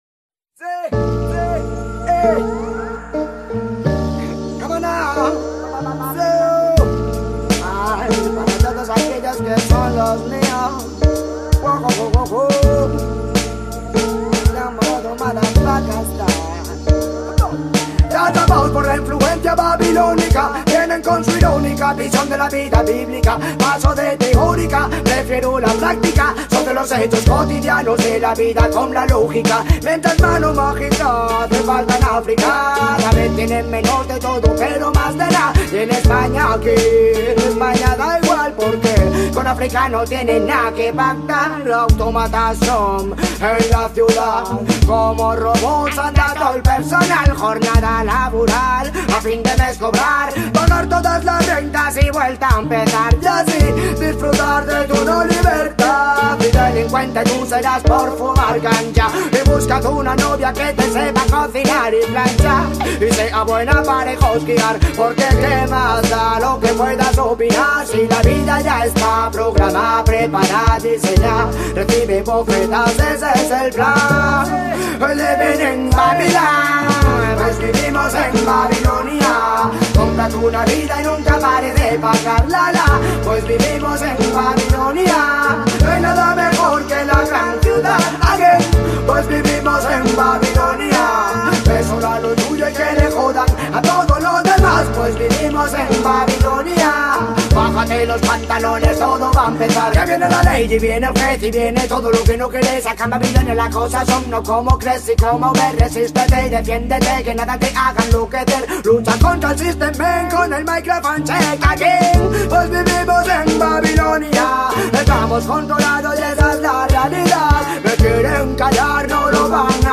🌿 Temi trattati nell’intervista:
🎤 L’intervista, registrata direttamente a Benicàssim, è un viaggio nella carriera di Morodo: dagli esordi nei sound system madrileni alla consacrazione come voce consapevole del reggae iberico. Si parla di lingua, identità, spiritualità e cultura urbana, con uno sguardo lucido sul ruolo della musica come strumento di trasformazione sociale.
Artista-a-la-Vista-Intervista-Morodo-Rototom-2025.mp3